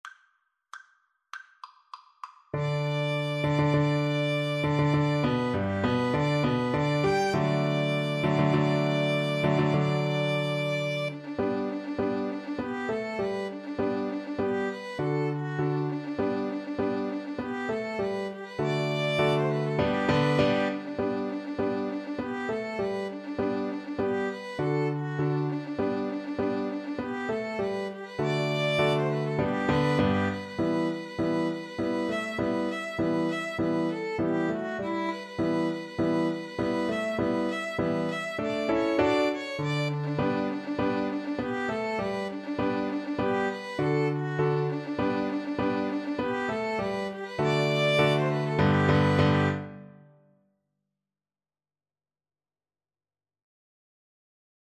G major (Sounding Pitch) (View more G major Music for Violin-Cello Duet )
4/4 (View more 4/4 Music)
Presto =200 (View more music marked Presto)
Violin-Cello Duet  (View more Easy Violin-Cello Duet Music)
Classical (View more Classical Violin-Cello Duet Music)